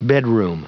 Prononciation du mot bedroom en anglais (fichier audio)
Prononciation du mot : bedroom